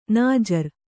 ♪ nājar